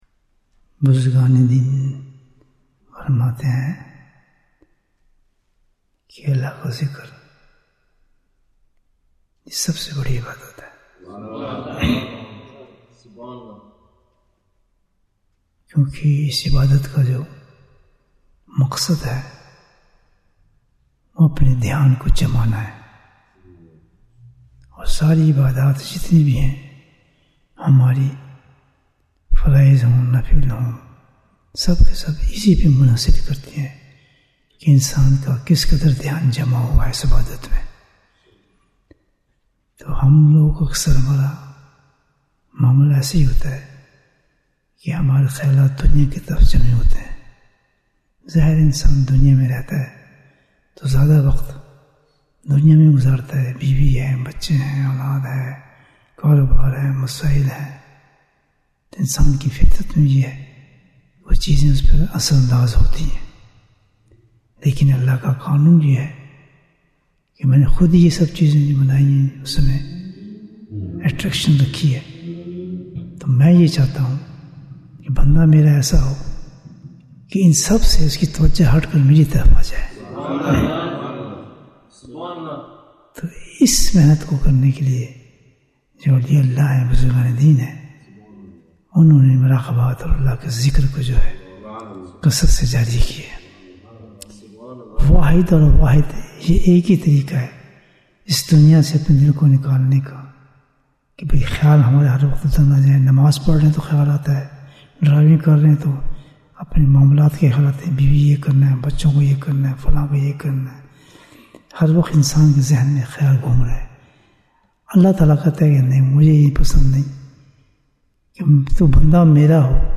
ذکر سے پہلے بیان 2110 minutes7th July, 2025